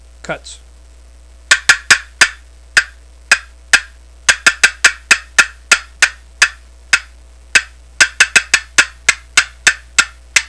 Listen to 11 seconds of cutts
• Produces extra loud, keen, raspy notes totally different from conventional boxes
southlandscreamincutts11.wav